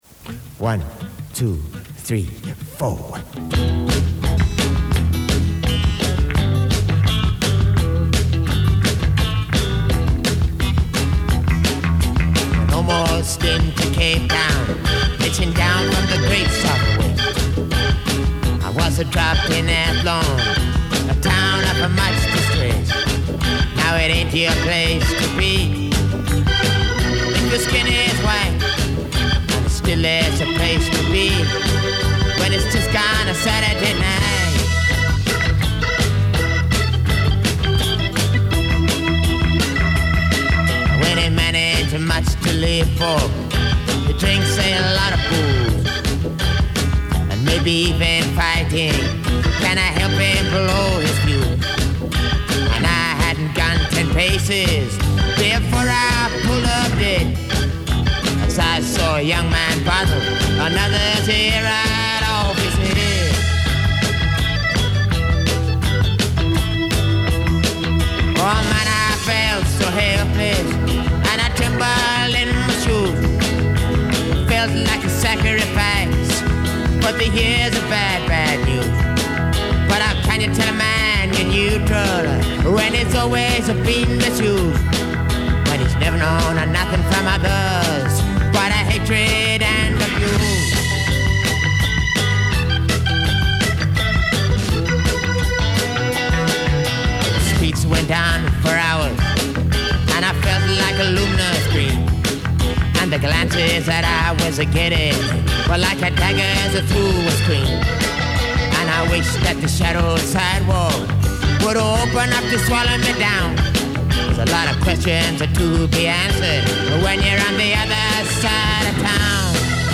piano and organ
bass
drums
Powerful track.